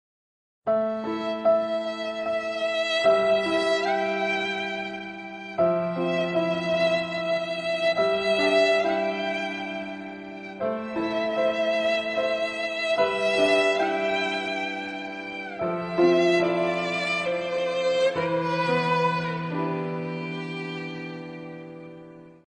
Грустная музыка из мема на скрипке из мема (неудача)